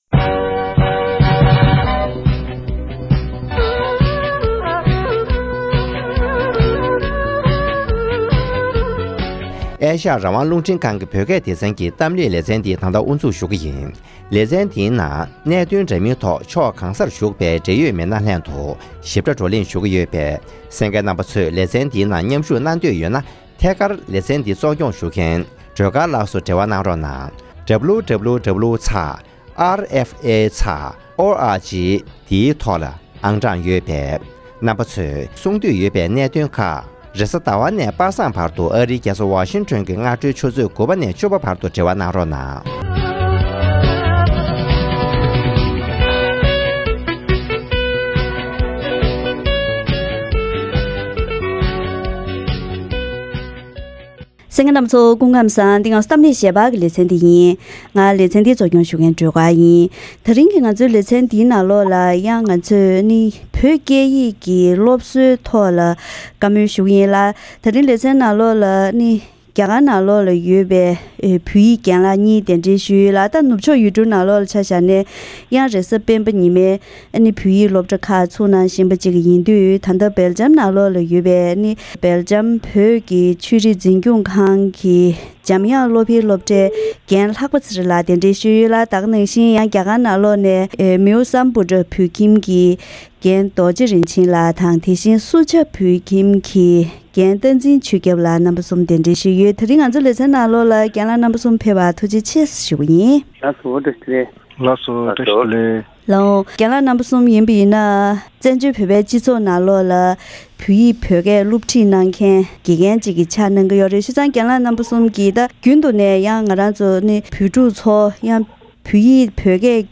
བོད་གྲྭ་ཁག་གི་བོད་ཡིག་དགེ་རྒན་གསུམ་པ་ལྷན་དུ་བོད་ཡིག་སློབ་ཁྲིད་ཀྱི་ཉམས་མྱོང་།